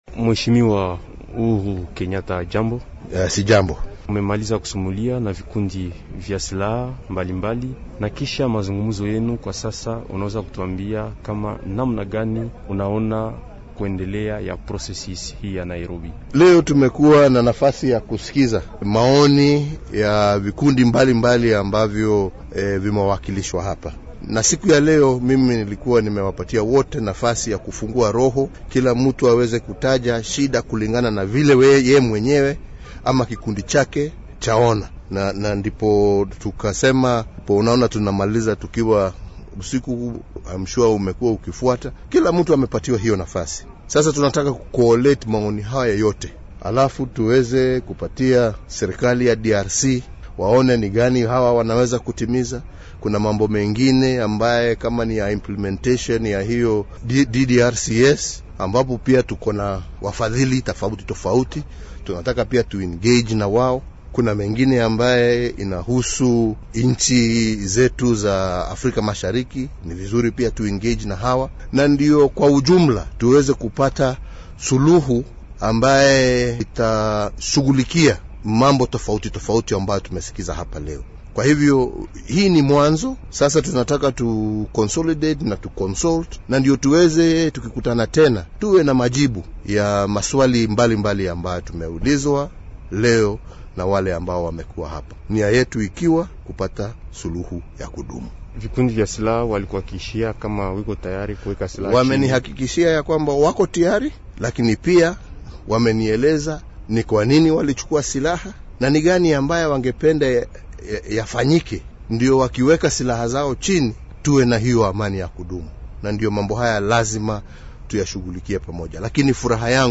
Mwezeshaji wa EAC wa mchakato wa amani mashariki mwa DRC,  Rais wa zamani wa Kenya Uhuru Kenyatta anazungumza kuhusu mazungumzo yake hii siku ya tatu Jumatano na wajumbe mbalimbali wa makundi yenye silaha kutoka Kivu Kaskazini, Kivu Kusini, Maniema, Ituri na Tanganyika. Anatangaza kwamba wote wamesema wako tayari kuweka chini silaha zao lakini kwa kusema nini kifanyike kwa amani ya kudumu.